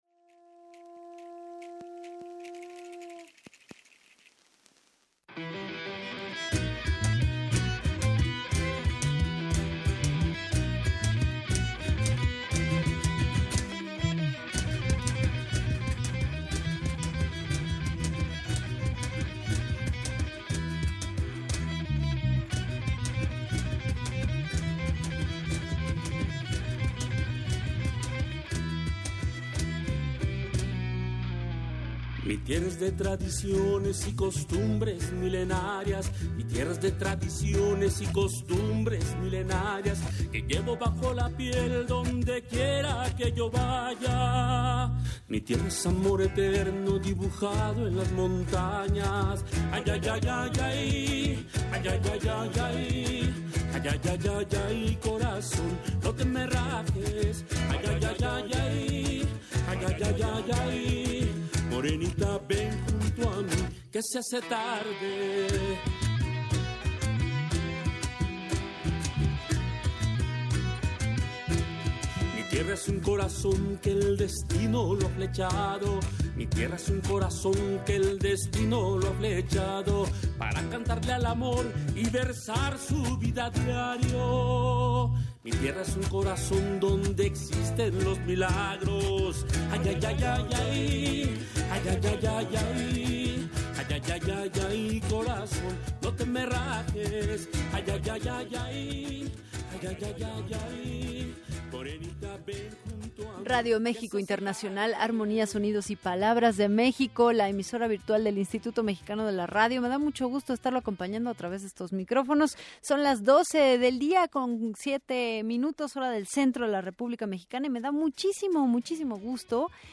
entrevista_portal_mixklan.mp3